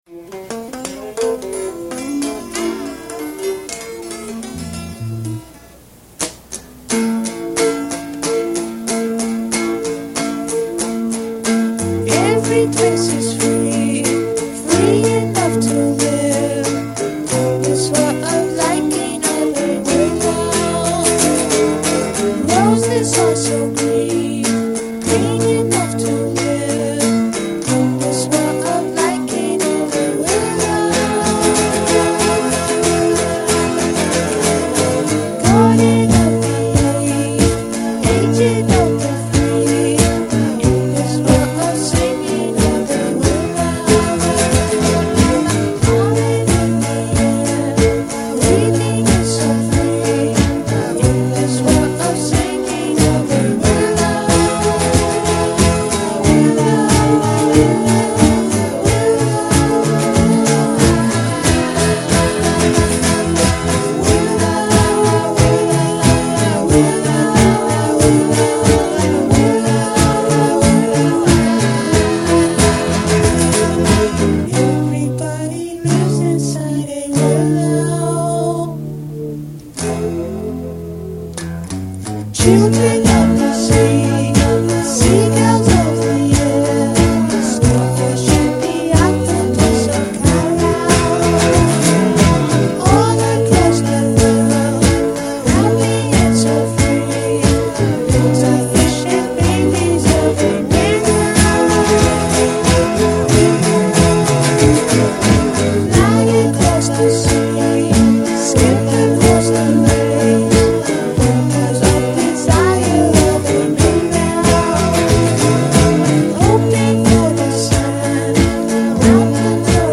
a trippy whimsical song